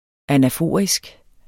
anaforisk adjektiv Bøjning -, -e Udtale [ anaˈfoˀʁisg ] Betydninger 1.